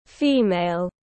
Nữ giới tiếng anh gọi là female, phiên âm tiếng anh đọc là /ˈfiː.meɪl/.